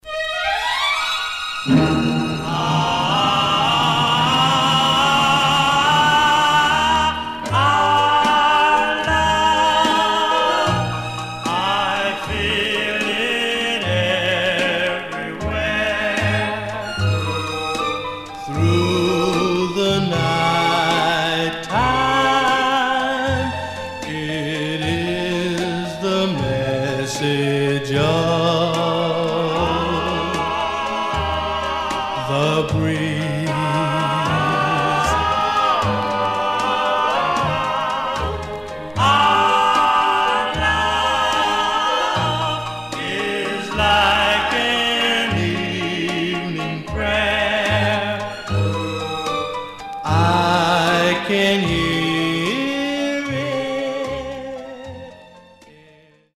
Some surface noise/wear
Mono
Male Black Group Condition